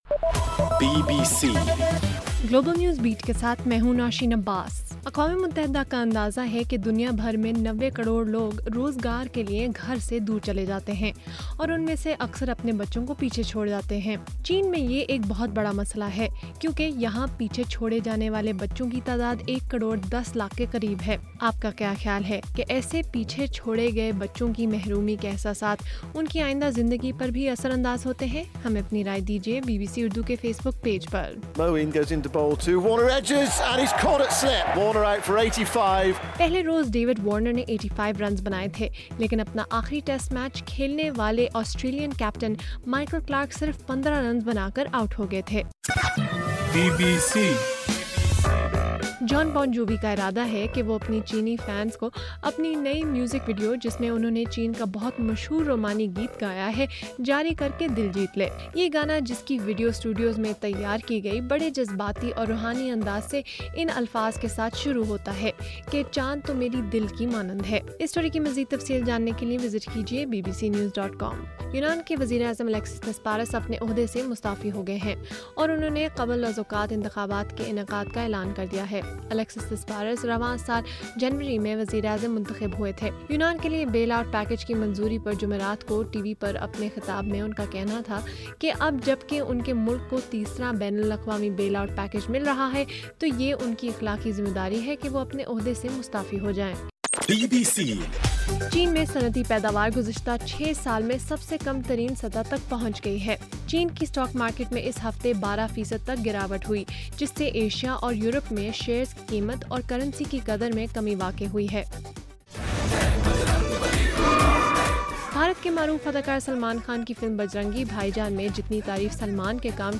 اگست 21: رات 8 بجے کا گلوبل نیوز بیٹ بُلیٹن